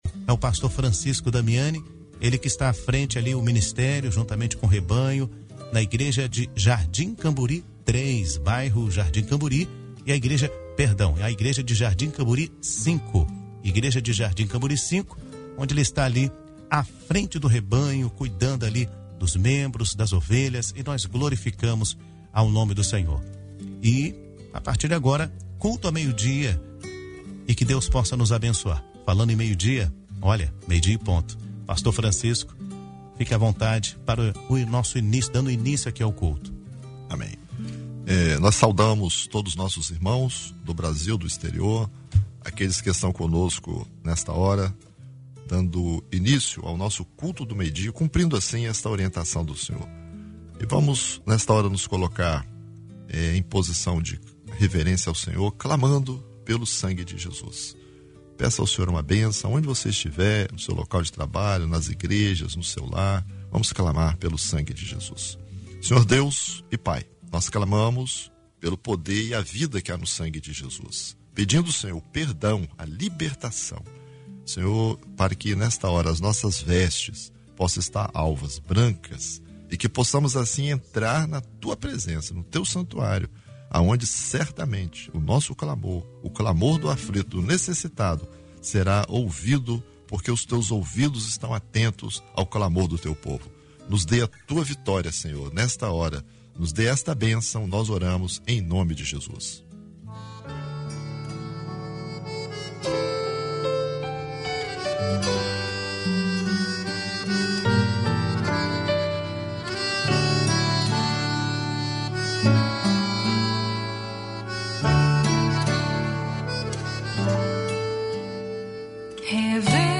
Culto de oração da Igreja Cristã Maranata.